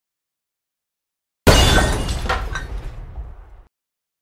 Crash Sound Eff Téléchargement d'Effet Sonore
Crash Sound Eff Bouton sonore